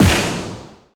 eat-da-rich-explosion.ogg